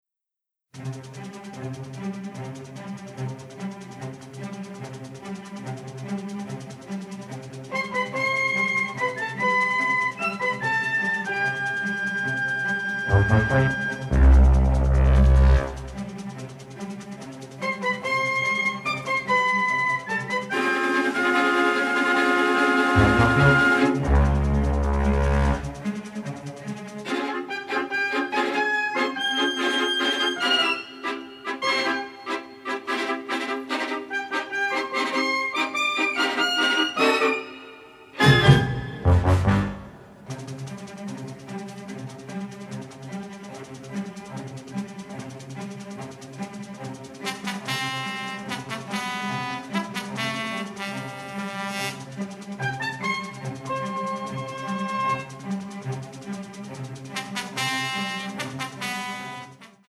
dynamic score